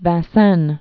(văɴ-sĕn)